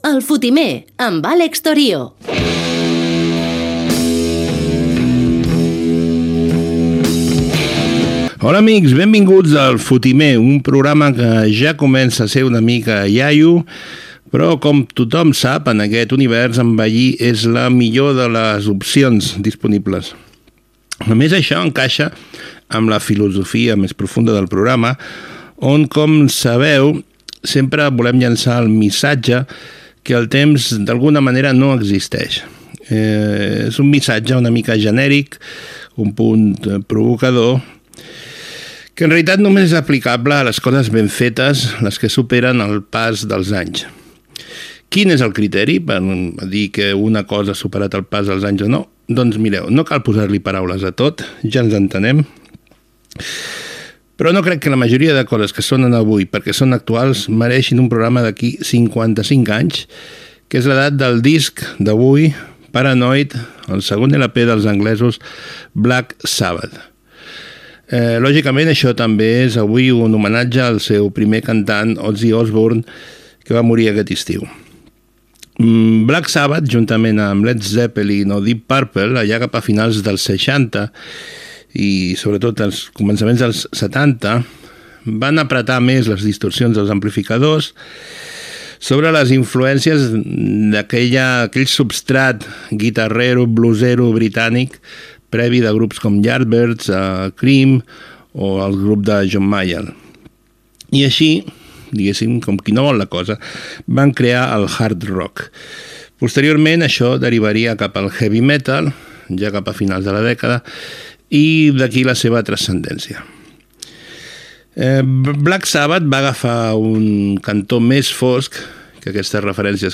Careta, presentació, filosofia del programa, presentació del tema que s'escoltarà
FM